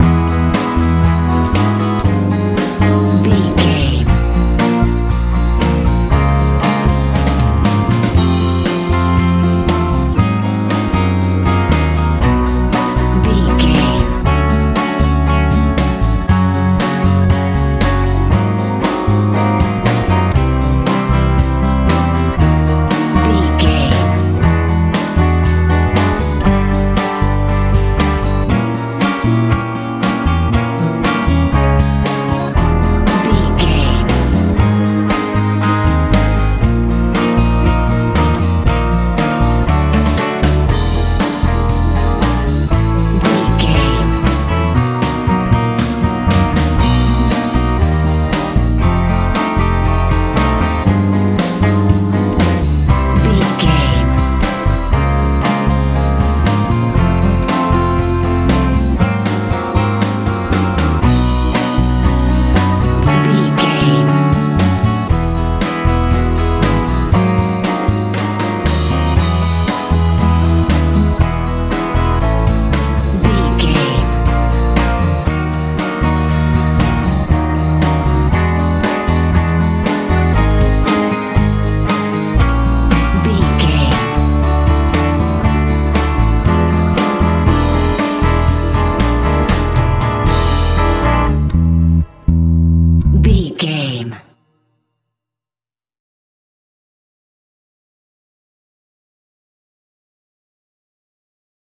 med rock feel
Ionian/Major
A♭
bright
organ
acoustic guitar
electric guitar
bass guitar
drums